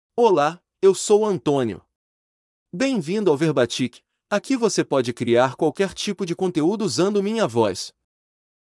Antonio — Male Portuguese (Brazil) AI Voice | TTS, Voice Cloning & Video | Verbatik AI
Antonio is a male AI voice for Portuguese (Brazil).
Voice sample
Male
Antonio delivers clear pronunciation with authentic Brazil Portuguese intonation, making your content sound professionally produced.